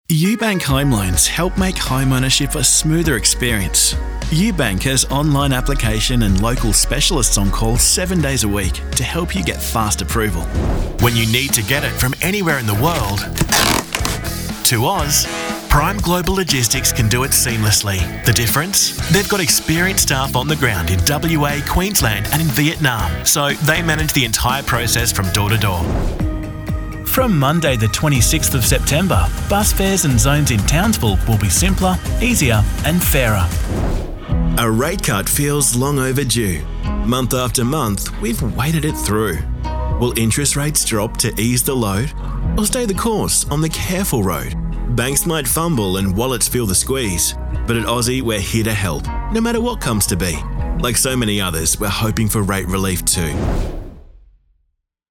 Young and fun, sophisticated and natural, pulled back and versatile….  he’s good to go when you’re ready!
• Natural Conversational